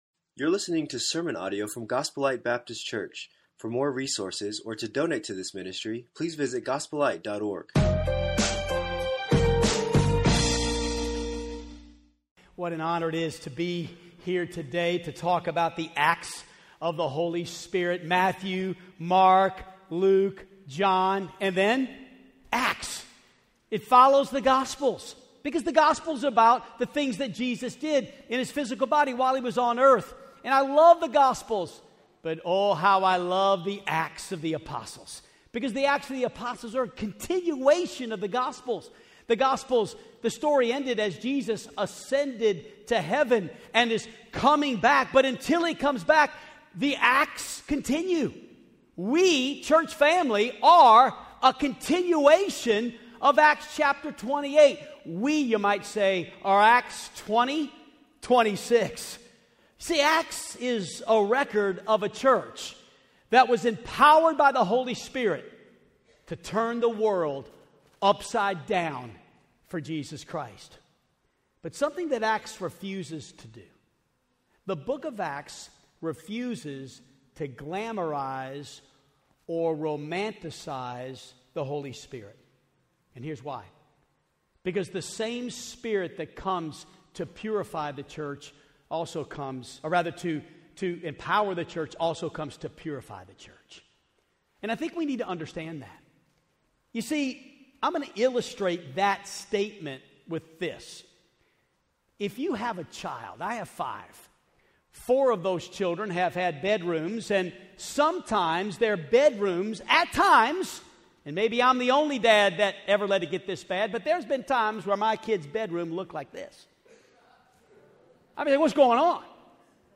Acts of the Holy Spirit - Sermon 5